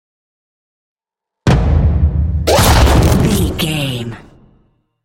Double hit with whoosh shot explosion
Sound Effects
heavy
intense
dark
aggressive
hits